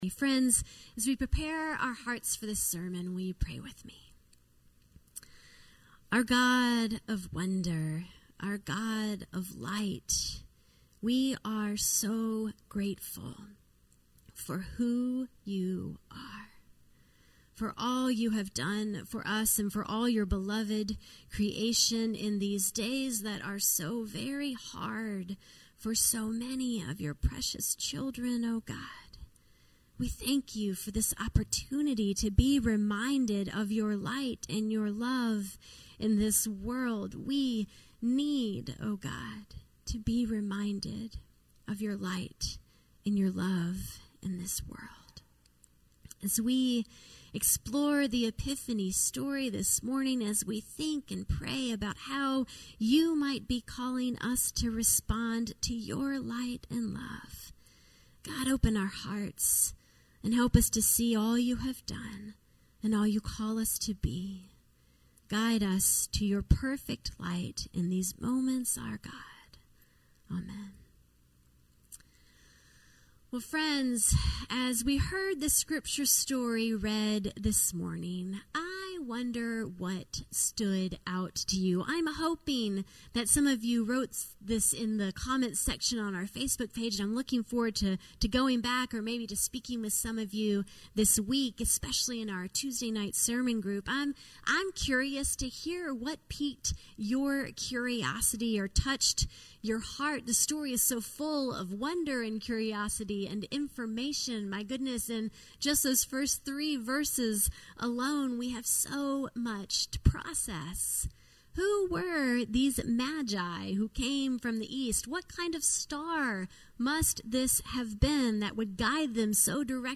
A message from the series "Seeking Jesus."